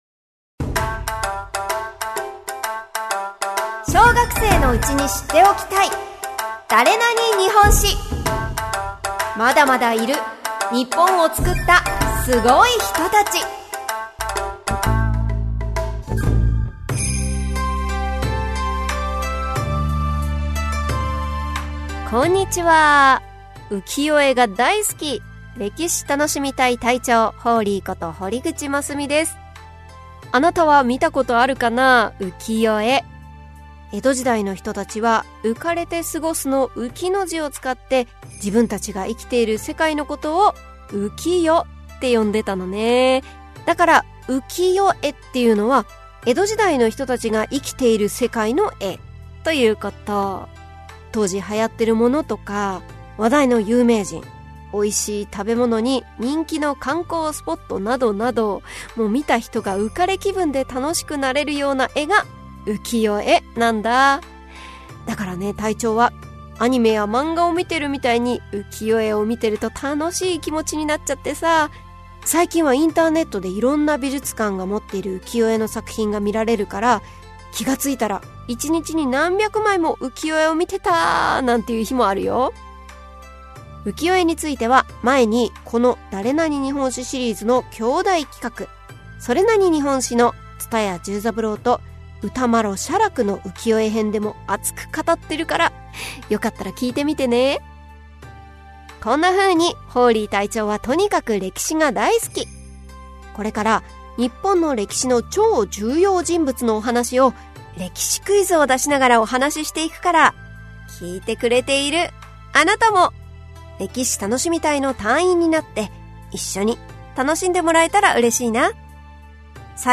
[オーディオブック] 小学生のうちに知っておきたい！